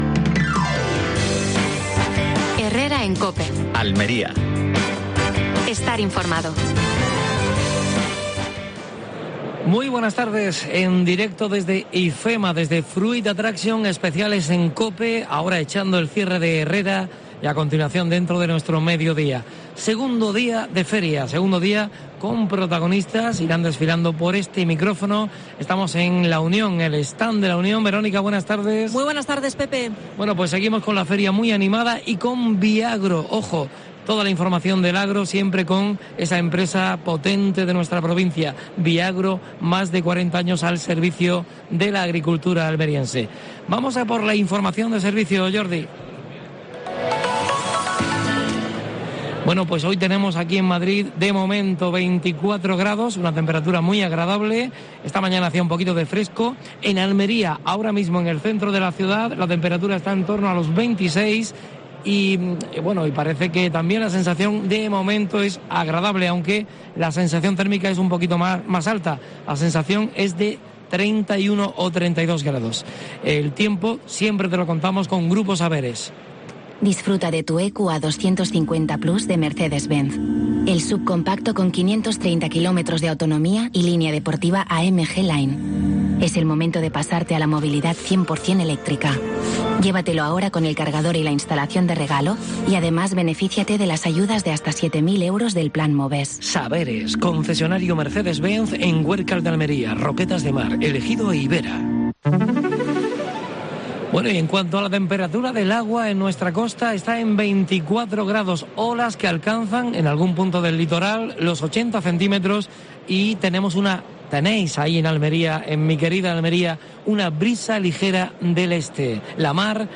AUDIO: Programa especial desde Fruit Attraction (Madrid).